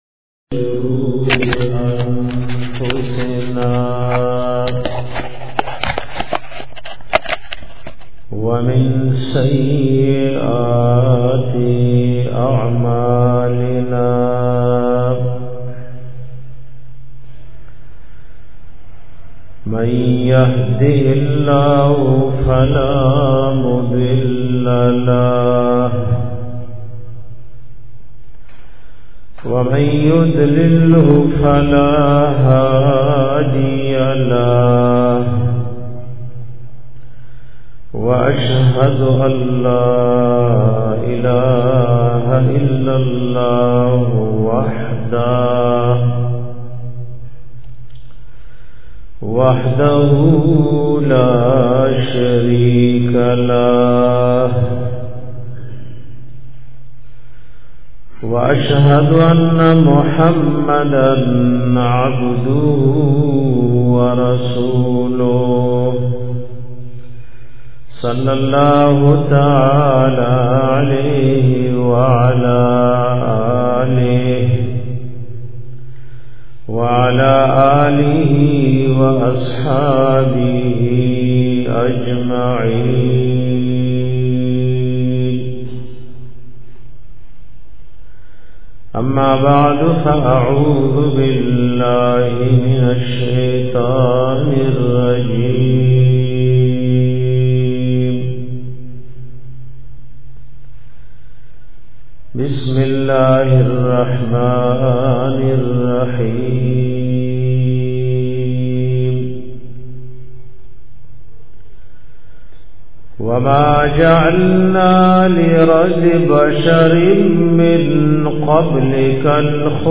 bayan pa bara da wafat da nabi s a no 2